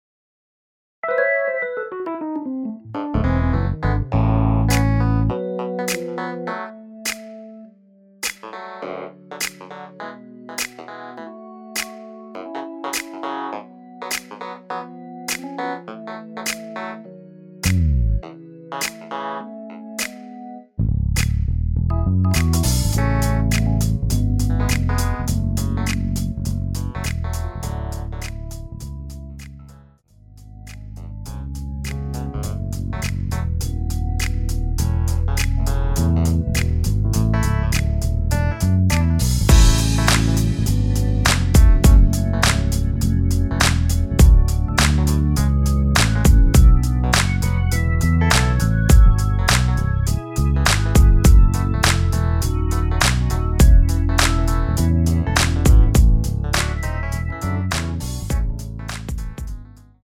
MR은 2번만 하고 노래 하기 편하게 엔딩을 만들었습니다.(본문의 가사와 코러스 MR 미리듣기 확인)
원키에서(-3)내린 MR입니다.
앞부분30초, 뒷부분30초씩 편집해서 올려 드리고 있습니다.
중간에 음이 끈어지고 다시 나오는 이유는